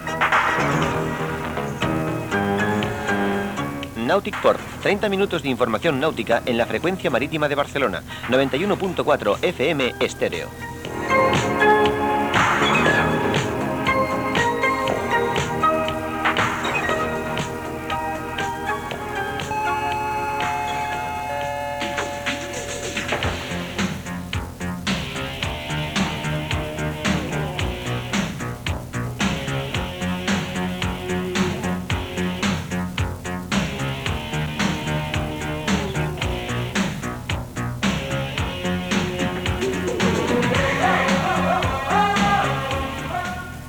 Indicatiu del programa i música.
FM